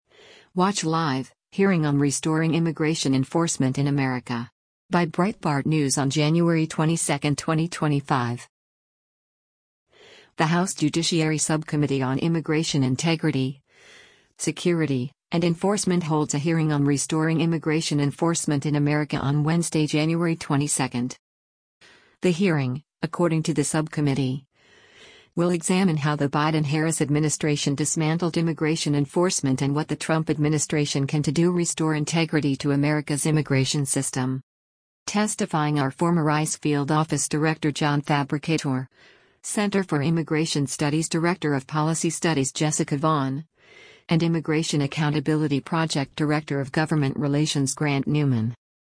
The House Judiciary Subcommittee on Immigration Integrity, Security, and Enforcement holds a hearing on “Restoring Immigration Enforcement in America”  on Wednesday, January 22.